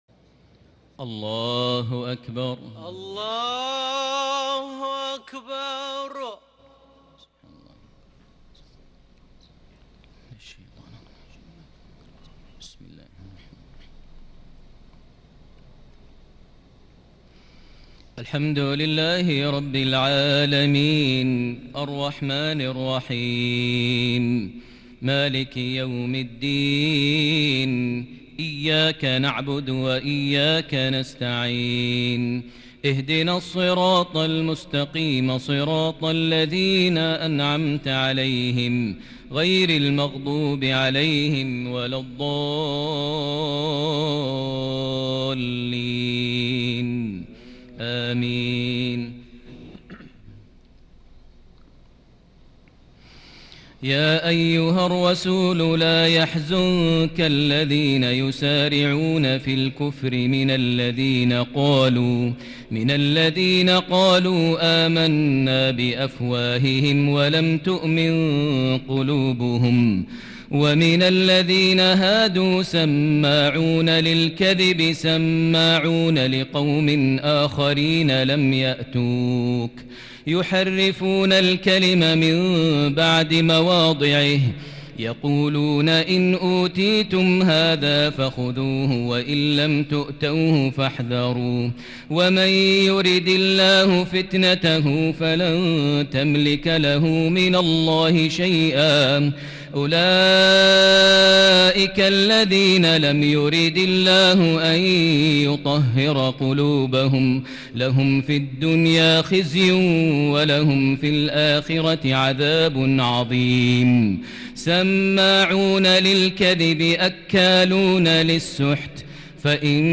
تراويح ليلة 8 رمضان 1441هـ من سورة المائدة {41-81} Taraweeh 8st night Ramadan 1441H Surah AlMa'idah > تراويح الحرم المكي عام 1441 🕋 > التراويح - تلاوات الحرمين